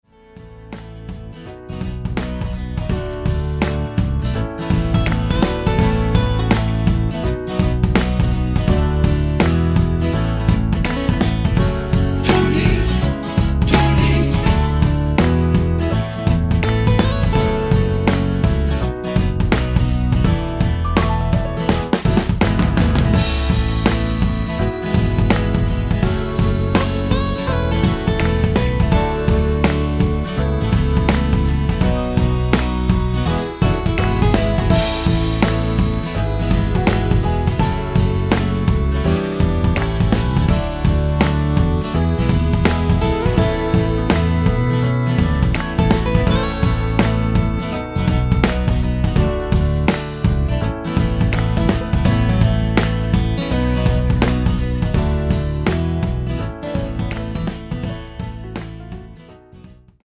둘이~ 하는 부분 코러스 추가된 MR 입니다.(미리듣기 참조)
앨범 | O.S.T
앞부분30초, 뒷부분30초씩 편집해서 올려 드리고 있습니다.
중간에 음이 끈어지고 다시 나오는 이유는